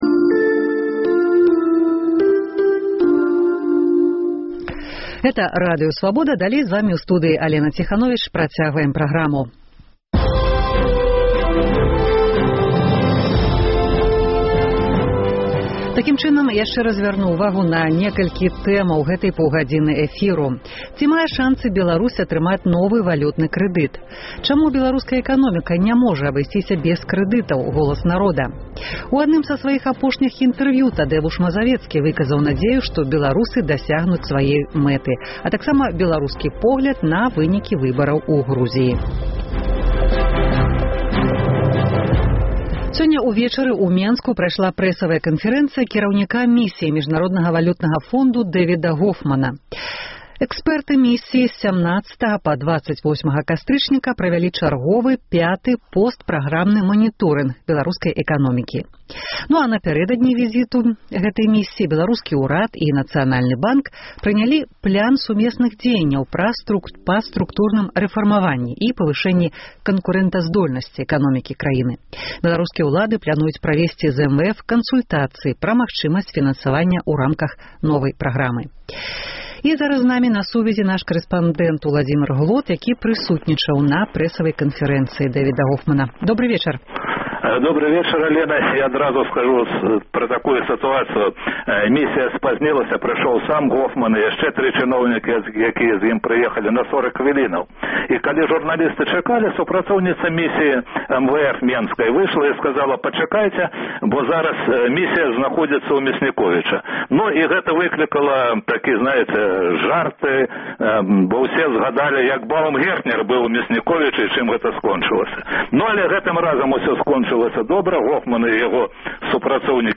Прэсавая канфэрэнцыя па выніках місіі МВФ, уключэньне карэспандэнта. Чаму беларуская эканоміка ня можа абысьціся без крэдытаў – голас народа.